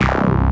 2_Bass.wav